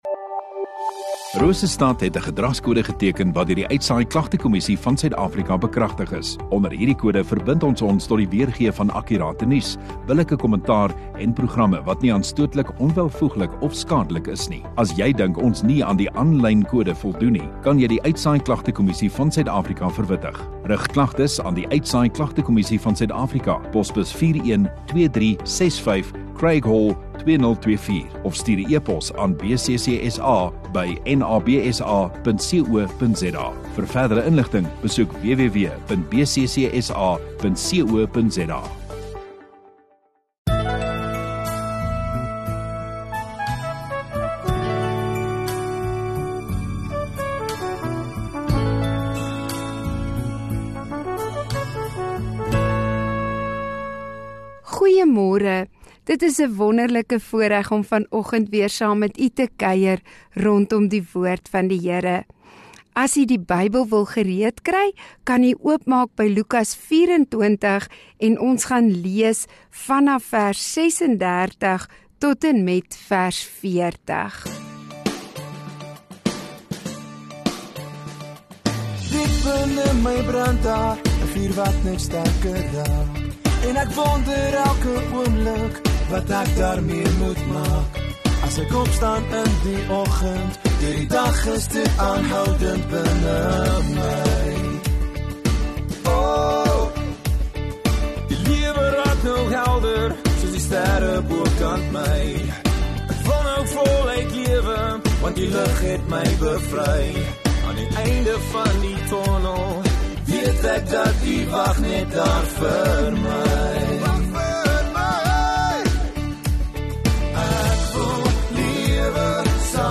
8 May Woensdag Oggenddiens